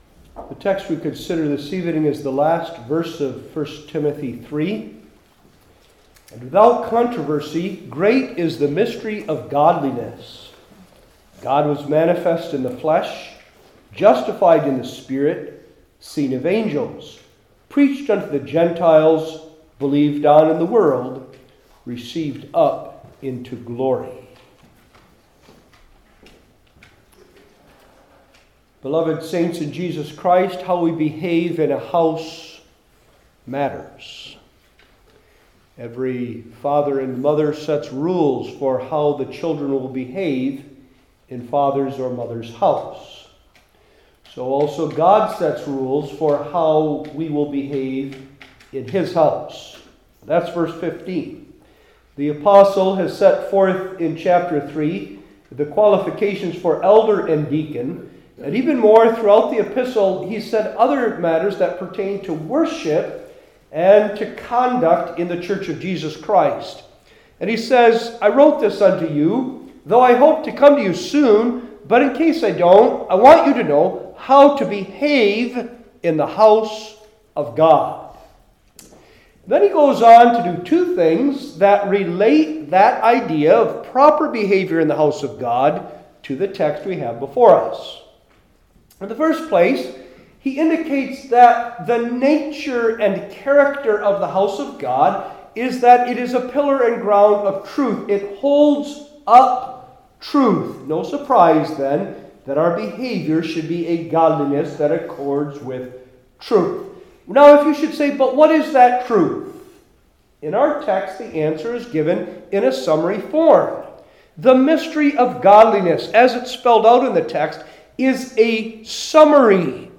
New Testament Individual Sermons I. The Mystery Revealed II.